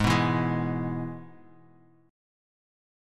Abm#5 chord